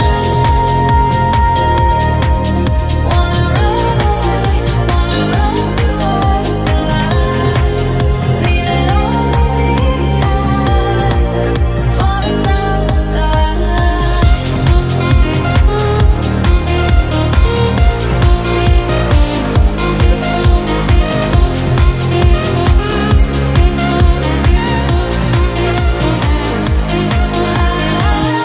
Genere: deep,dance,news